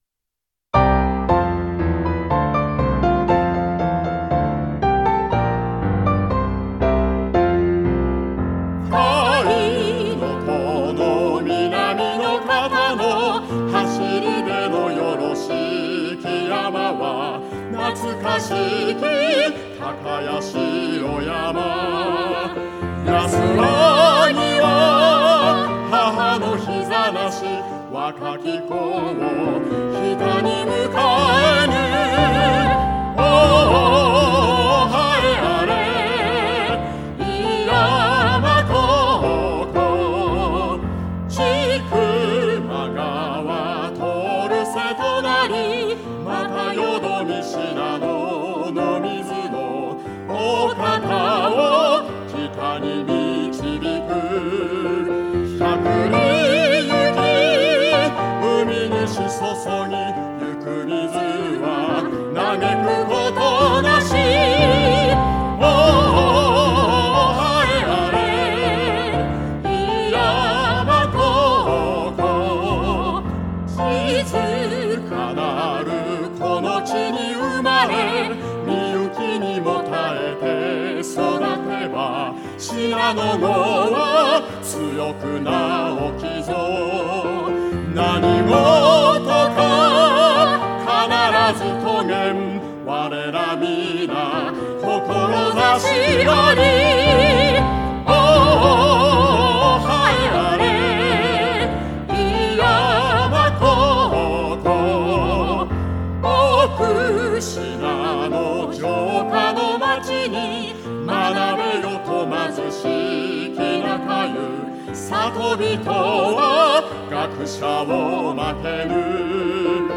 歌唱入り校歌
歌唱入り校歌.mp3